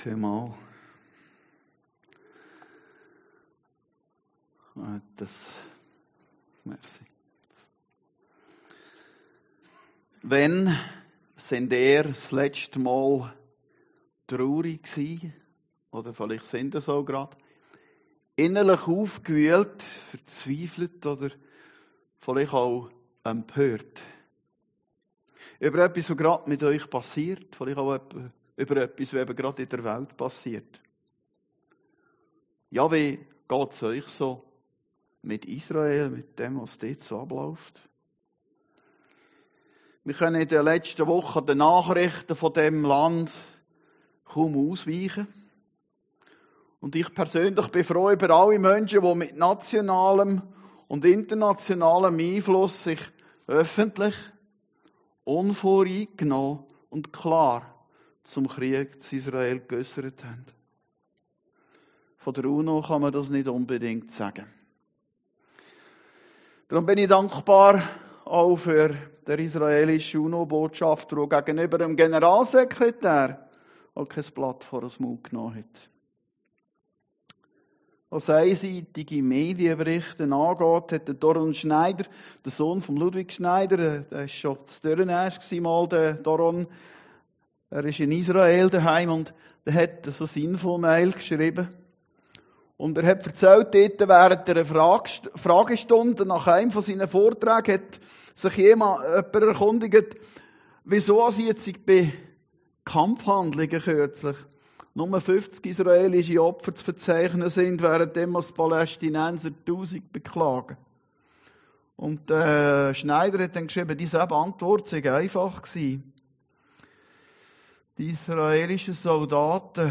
Predigten Heilsarmee Aargau Süd – Tröstet, tröstet mein Volk